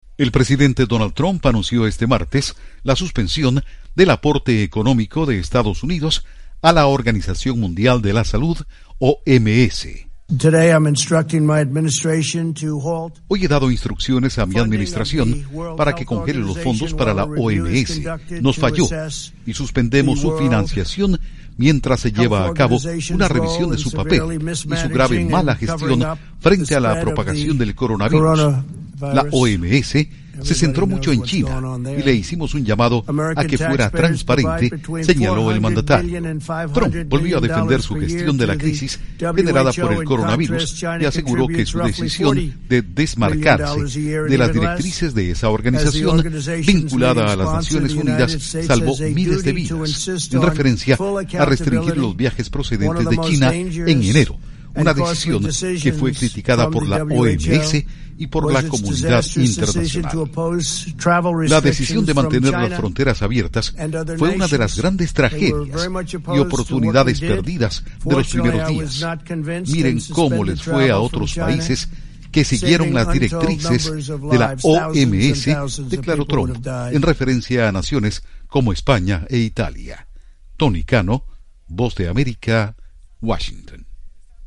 AudioNoticias
Intro: Trump suspende aporte de Estados Unidos a la Organización Mundial de la Salud Trump suspende aporte a la OMS. Informa desde la Voz de América en Washington